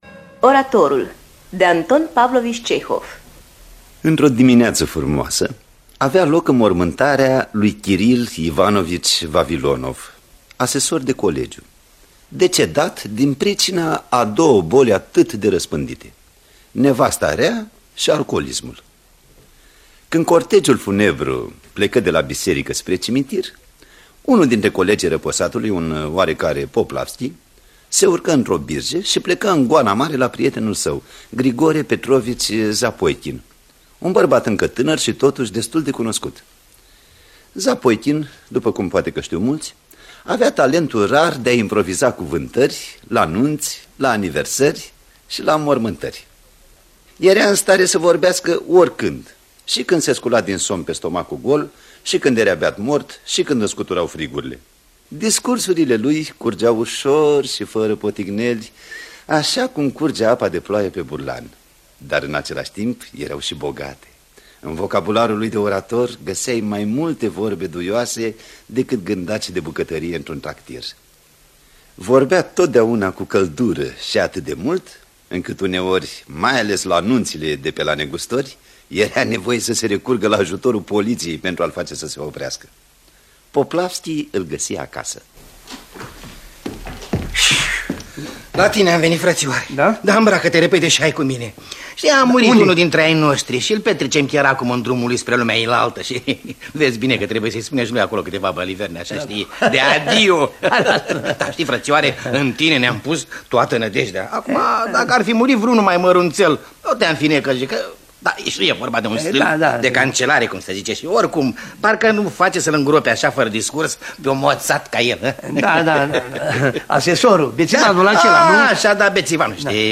Oratorul de Anton Pavlovici Cehov – Teatru Radiofonic Online